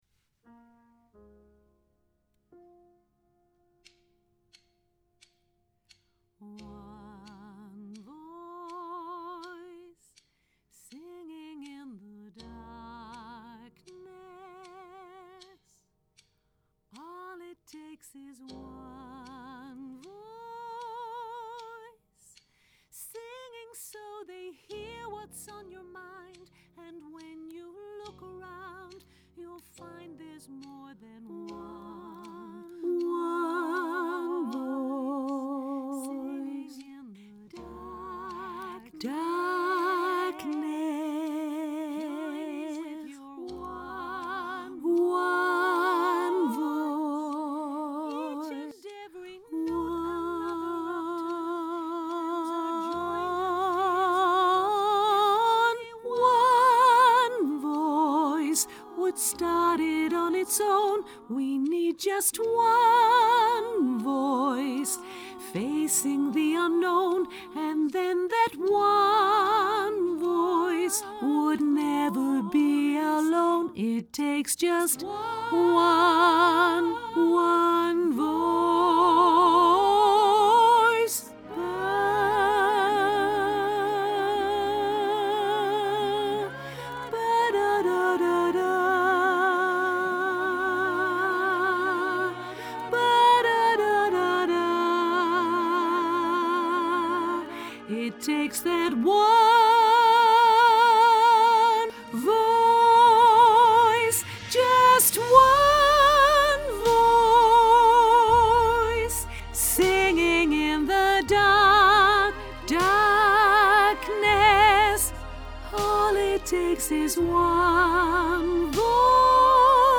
mezzo sopraan